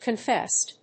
音節con・féssed 発音記号・読み方
/kʌˈnfɛst(米国英語), kʌˈnfest(英国英語)/